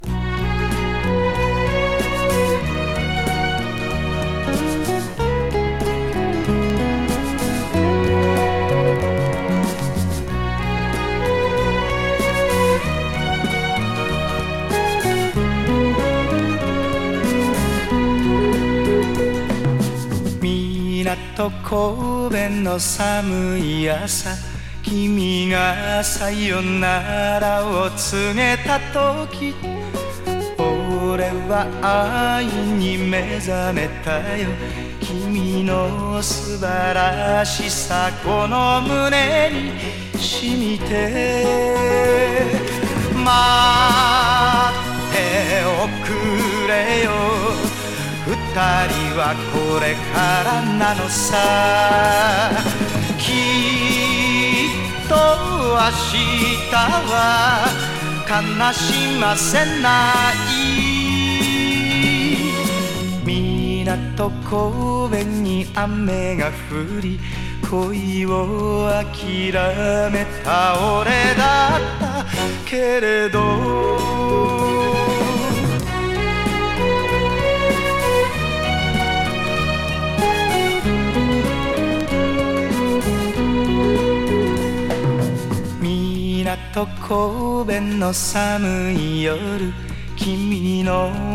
親しみやすいメロディーの込み上げ歌謡。